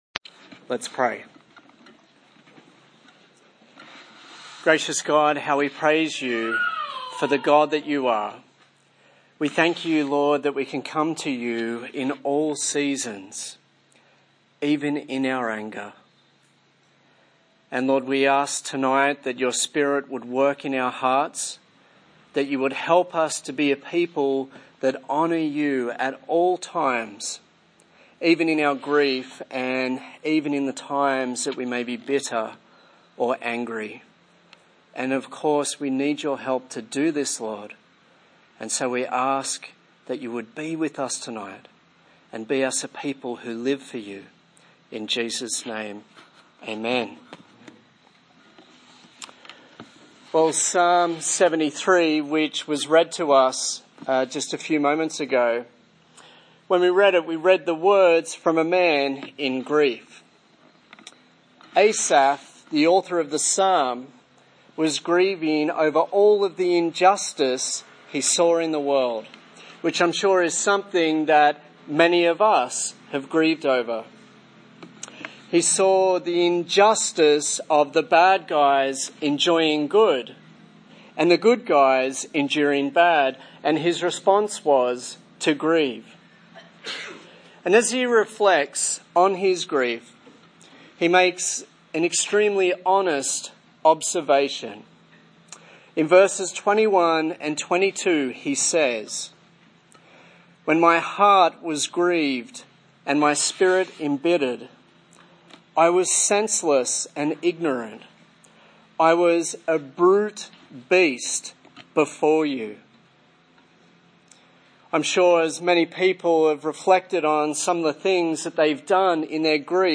Series: Grief - Redeeming Broken Glass Passage: Psalm 73:21-28 Service Type: TPC@5 A sermon in the series on Grief